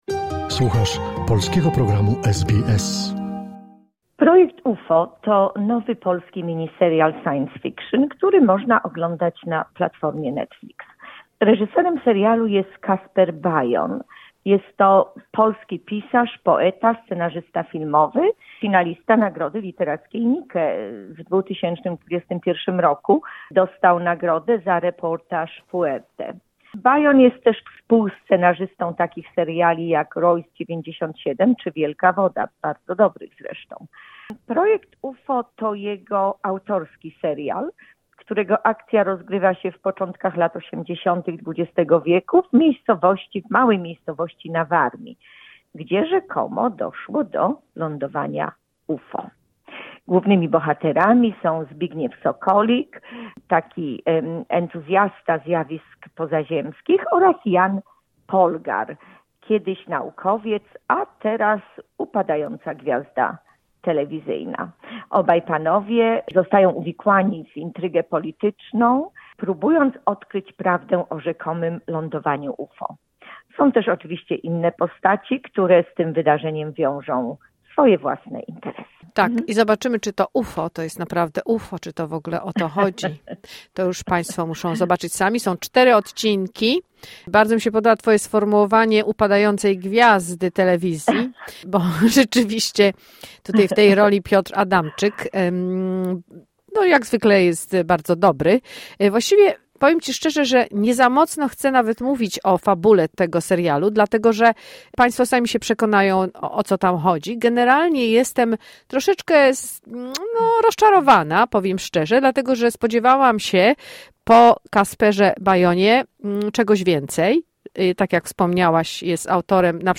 "UFO" - recenzja filmowa